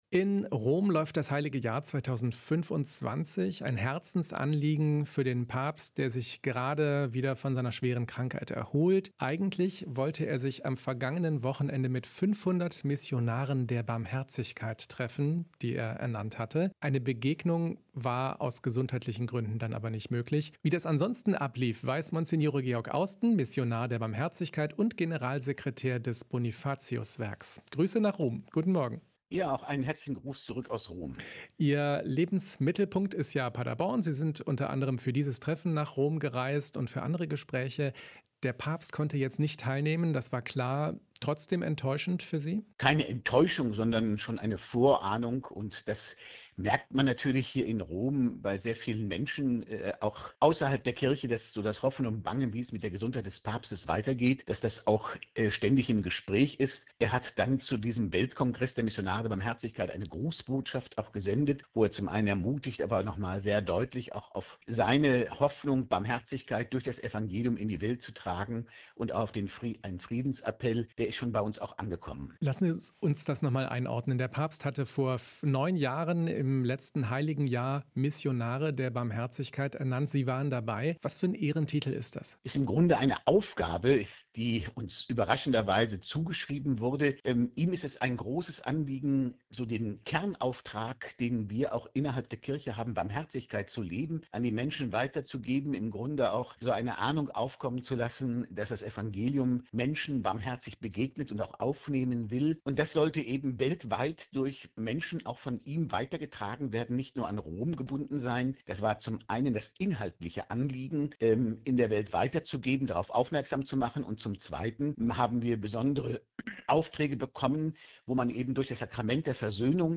Missionar der Barmherzigkeit berichtet vom Treffen in Rom